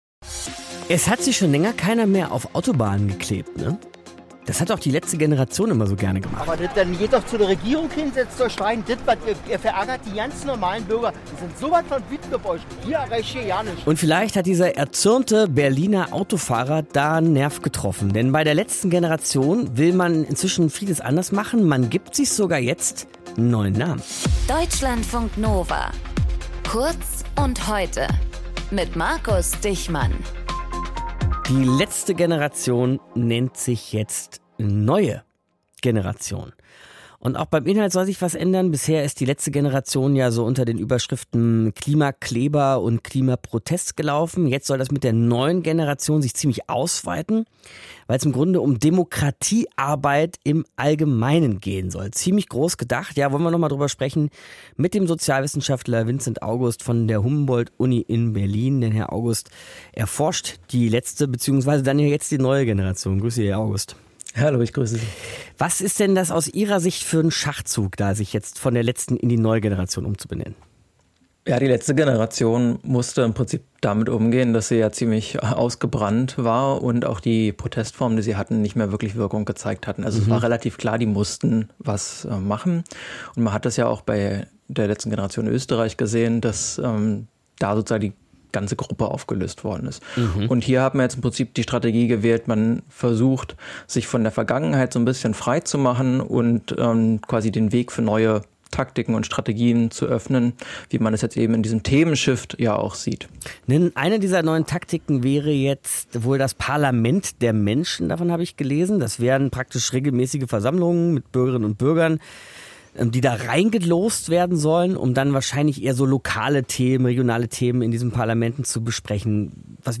Geoengineering, Wettermanipulation, Quantencomputer – diese Themen greift die Ausstellung in Frankfurt am Main auf. Interview